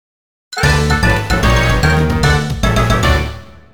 Âm thanh Phụ đề Có Hậu cho hoạt ảnh vui nhộn
Thể loại: Âm thanh chuyển cảnh
am-thanh-phu-de-co-hau-cho-hoat-anh-vui-nhon-www_tiengdong_com.mp3